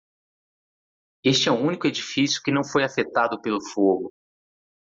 Pronounced as (IPA)
/a.feˈta.du/